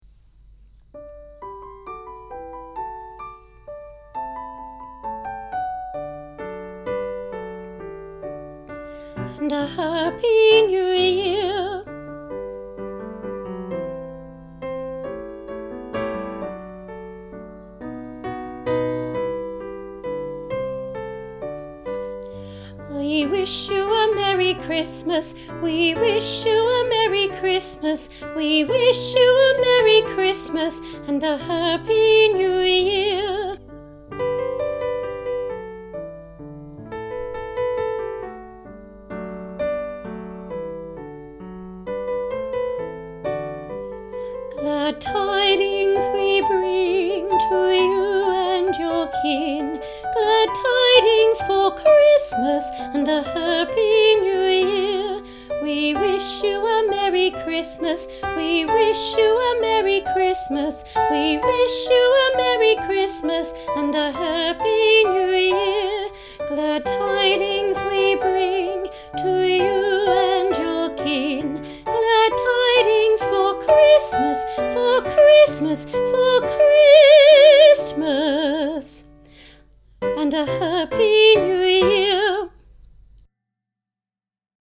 Elementrary Part – We Wish You A Merry Christmas